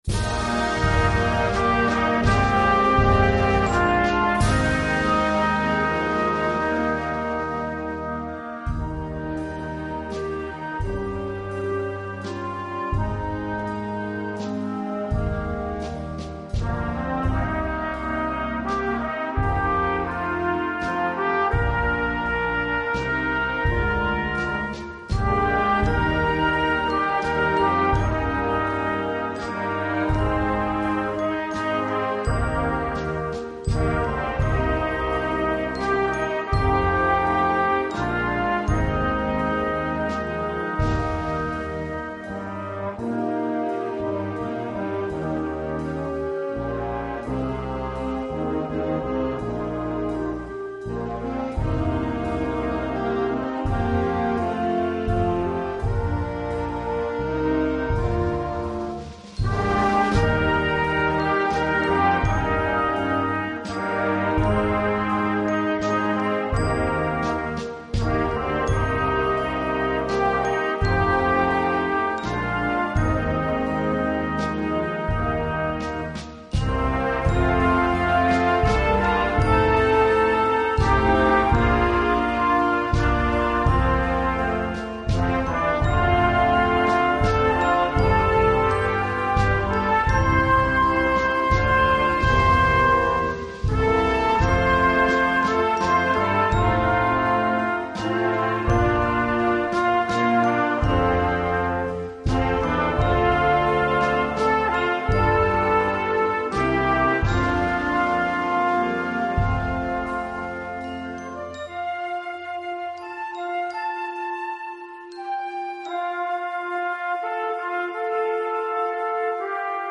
Blasmusik für Jugendkapelle
Besetzung: Blasorchester